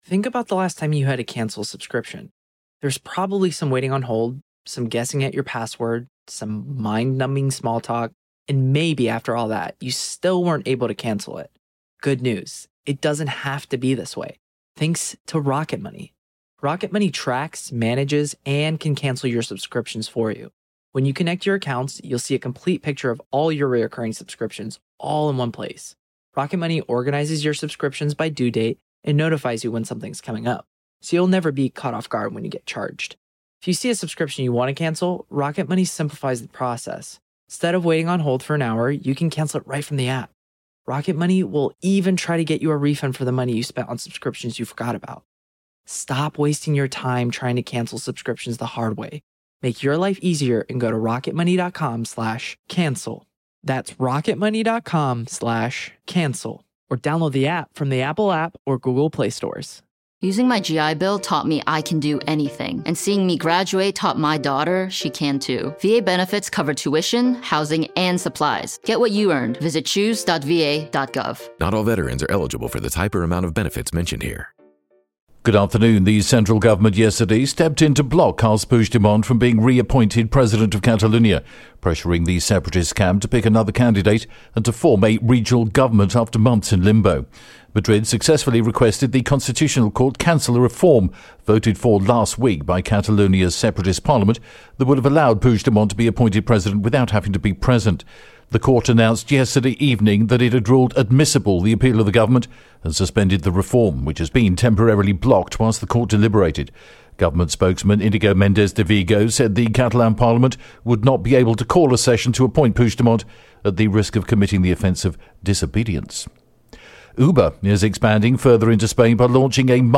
The latest Spanish News Headlines in English: May 10th pm